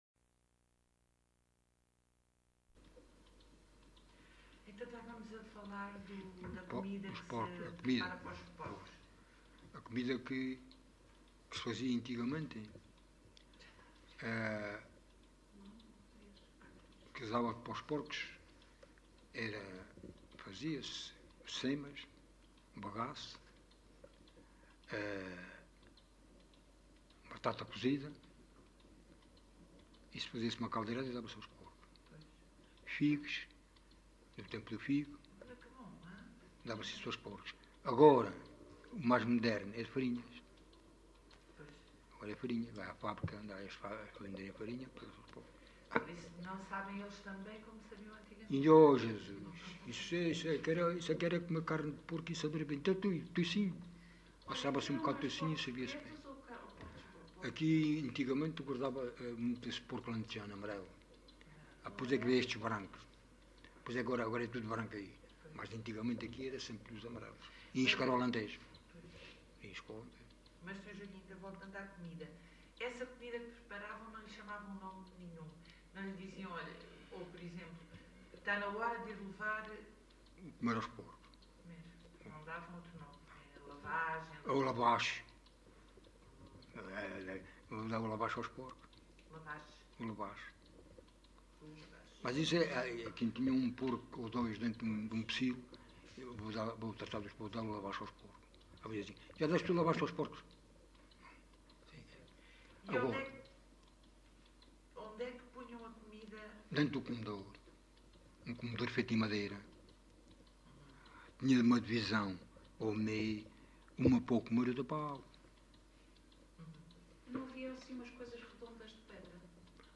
Text view Alcochete, excerto 30 Localidade Alcochete (Alcochete, Setúbal) Assunto O porco e a matança Informante(s